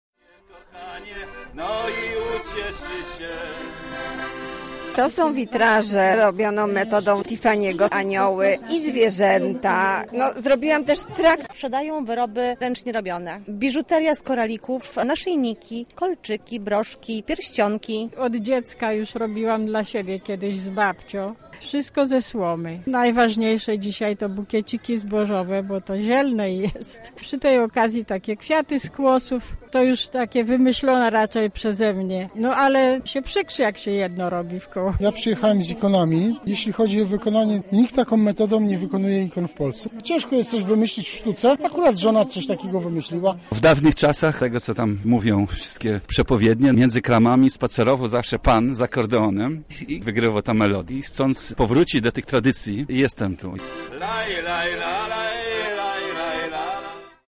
Nasza reporterka odwiedziła najciekawsze stoiska i specjalnie dla naszych słuchaczy porozmawiała z ich właścicielami:
Kiermasz